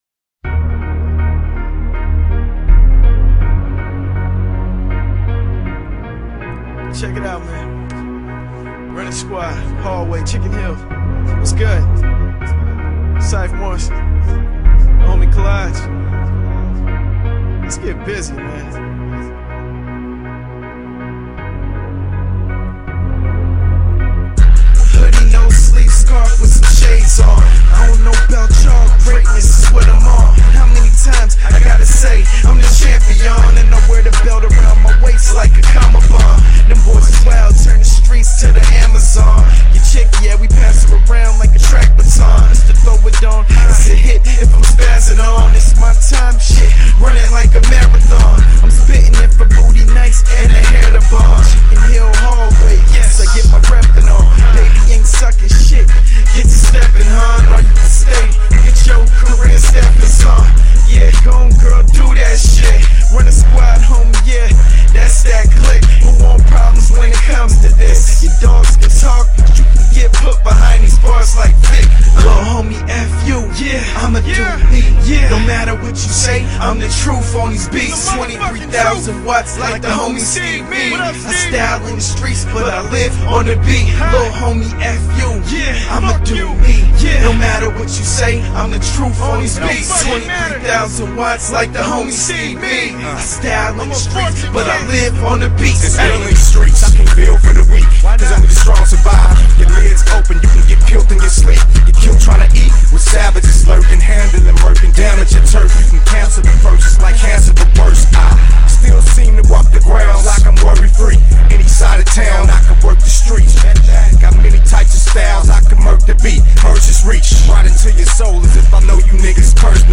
Ich lad den später noch mal hoch ;) Der drückt wie sau.